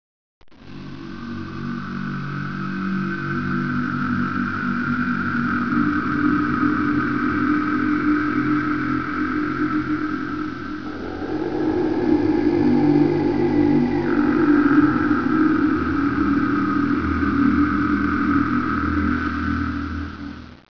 WAVHear the concordanach's plaintive call (230K)